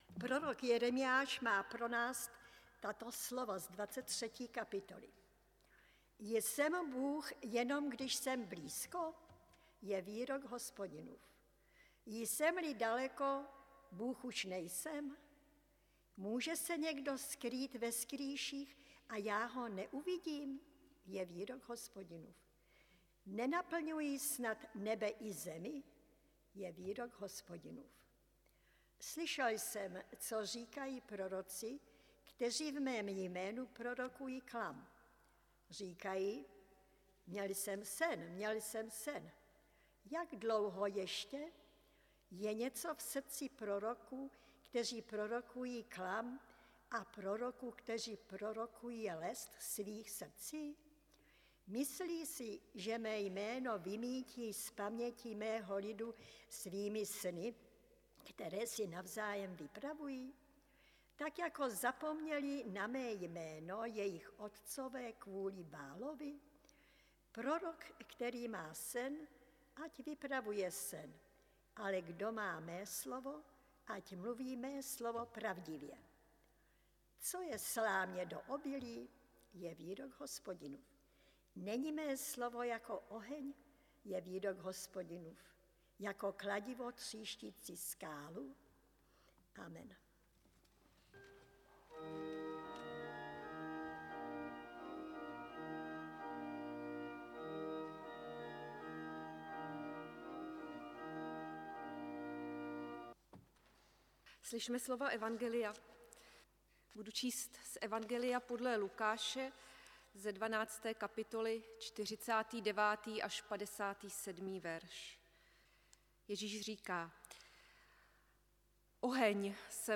záznam kázání Lukášovo evangelium 12, 49-57